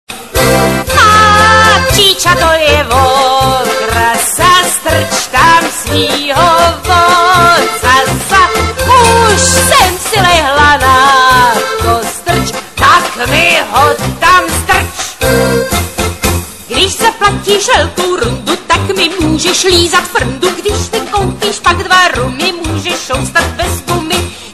nemravná pesnička 0:24